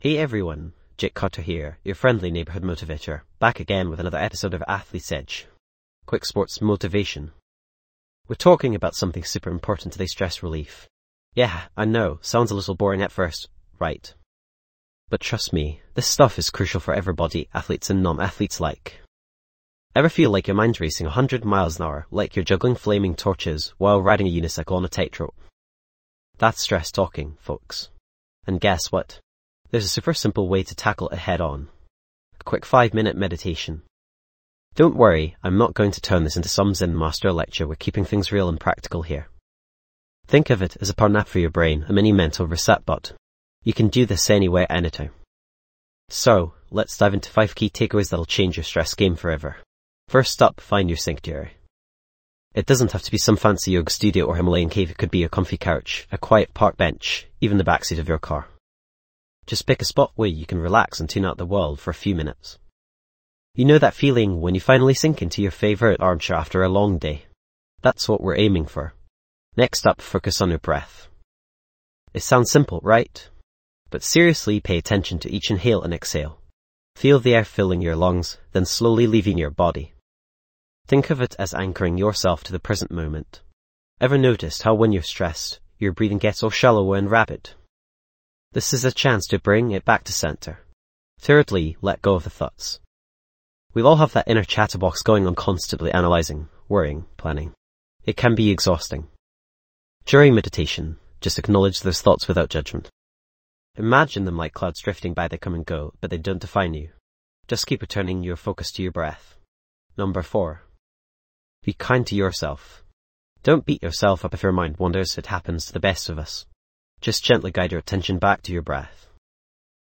Unwind and rejuvenate with a 5-minute guided meditation designed to help you combat stress and find inner peace. This quick, yet powerful technique will equip you with tools to manage stress effectively, enhance focus, and foster a sense of calm in your daily life.
This podcast is created with the help of advanced AI to deliver thoughtful affirmations and positive messages just for you.